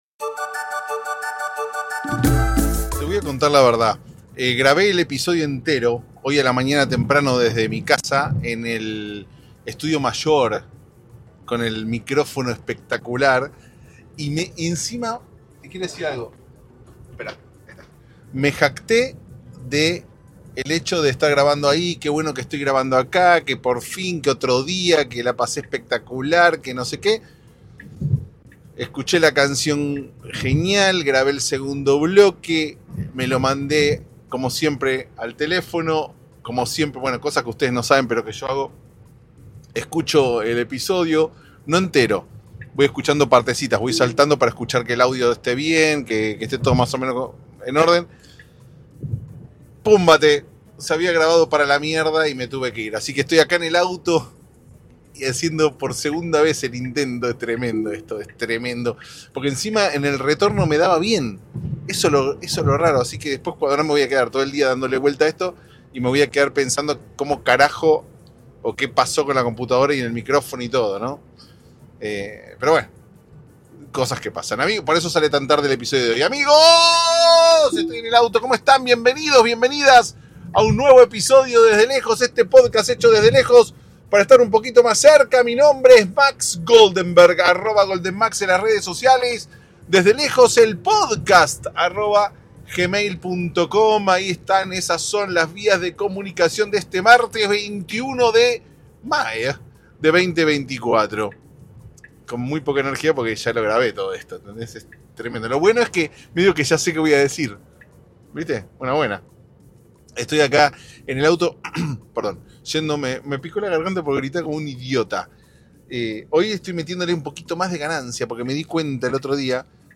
Créase o no, este episodio se grabó dos veces. La primera se escuchaba tan mal que parecía una secuestrado en el baúl de un auto.